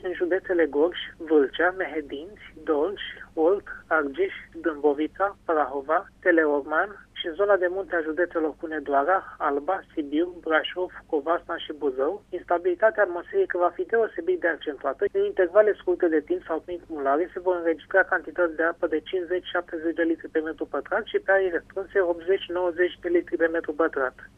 Meteorologul